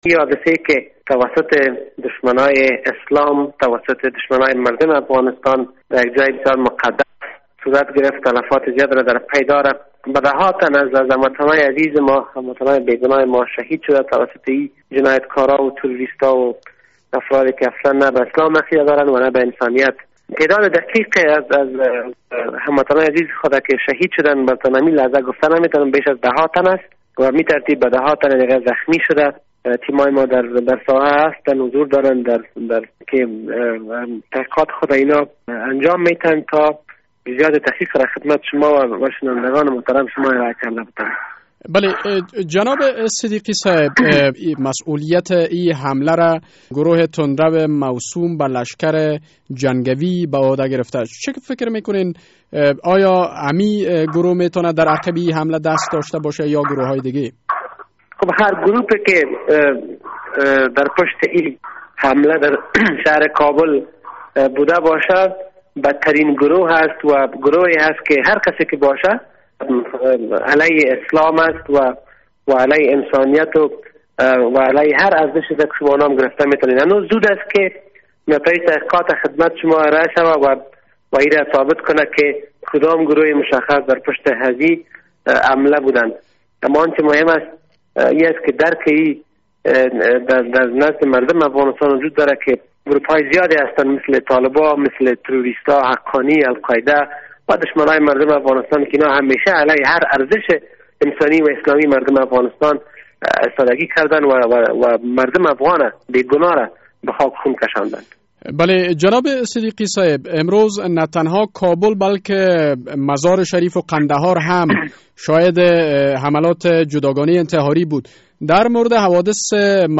مصاحبه در مورد تلفات حملهء انتحاری کابل